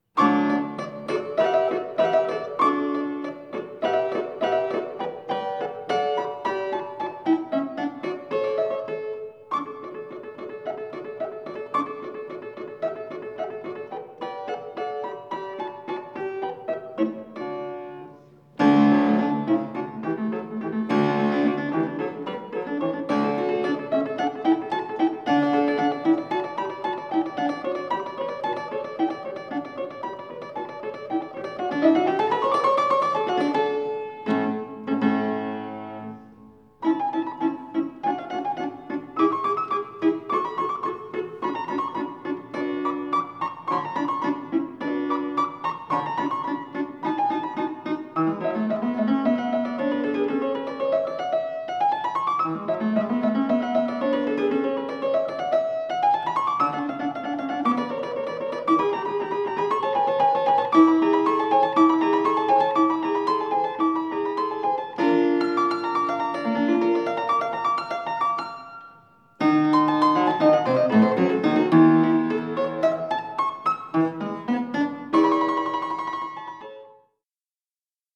pianoforte